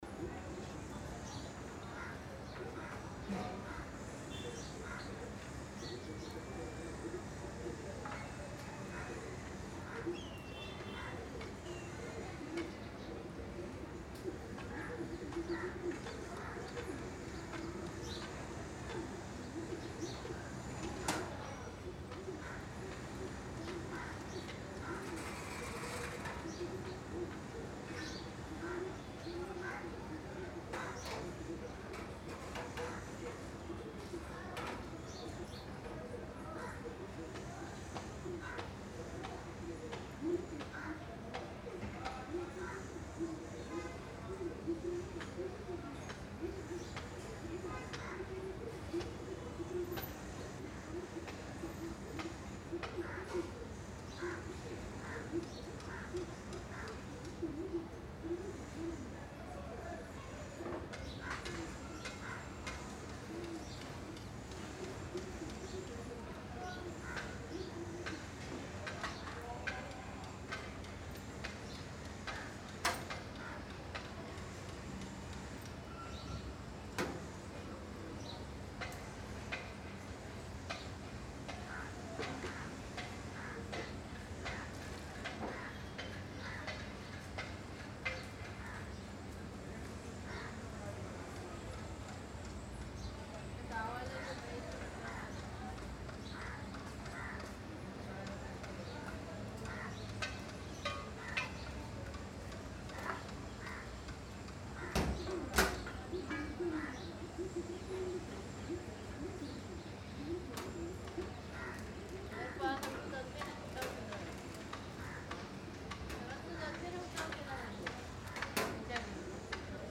Outdoor Hustle-AMB-022
Experience the natural liveliness of outdoor city hustle with this rich ambience track. It blends everyday environmental sounds including distant people talking, soft birds chirping, far vehicle movement, subtle repair noise, footsteps, vendor activity, and natural outdoor textures.
This ambience is recorded and mixed with clean, balanced layers to keep the atmosphere realistic yet smooth.
• Distant people talking
• Birds chirping and natural elements
• Far vehicles passing
• Light construction/repair sounds
• Footsteps & soft crowd movement
• Vendor/market tones
• Outdoor air and windy textures
• Subtle street-life echoes
Stereo
Outdoor, City Ambience
Clean, minimal background noise